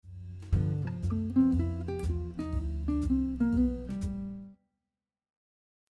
This jazz lick uses upper extension chord tones within the scale.
We finished the phrase in descending scale motion while mixing in some chromatic approach notes.
jazz lick using upper extension chord tones